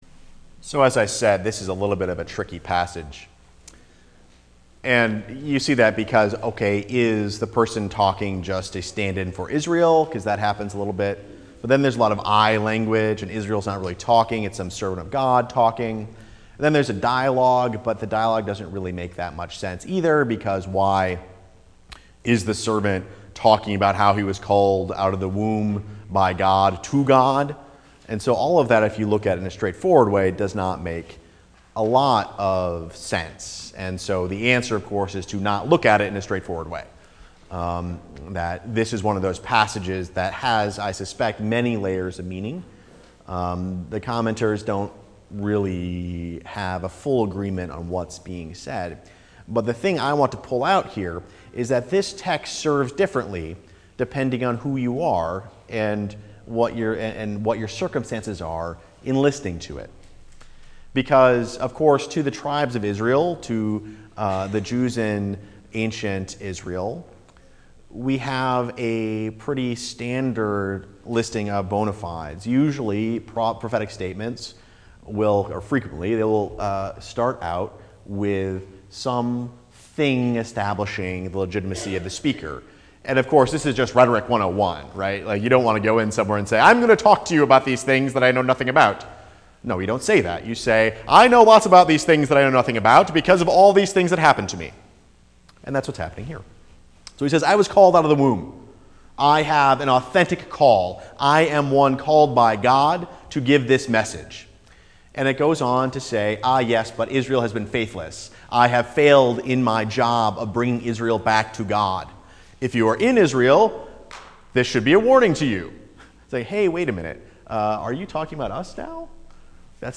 Preached on January 19, 2014 in Ankeny UCC Sermon Text: Isaiah 49:1-7